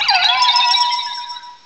pokeemerald / sound / direct_sound_samples / cries / uxie.aif